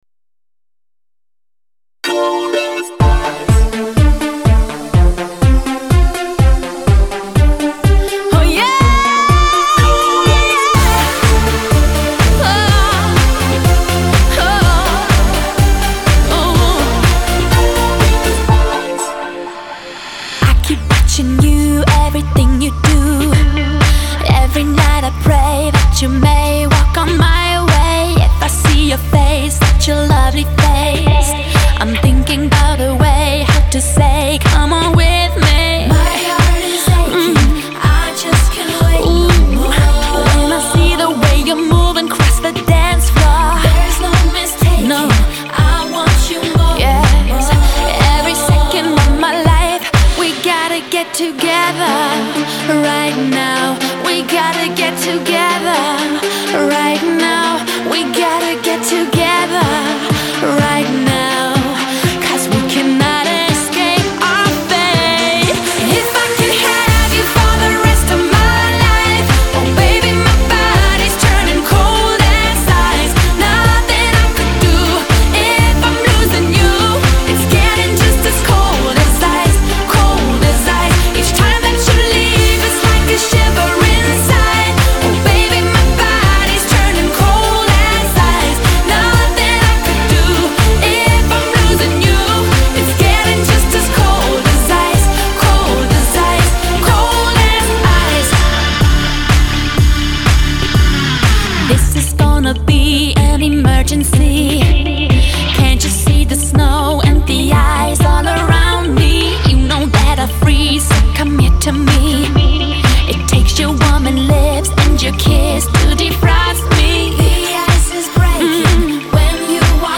[欧美流行]
温馨提示：日本JVC特别低音处理，试听时注意调节BASS(低音)
不错不错，音乐动感十足谢谢楼主分享
动感十足，激情四射。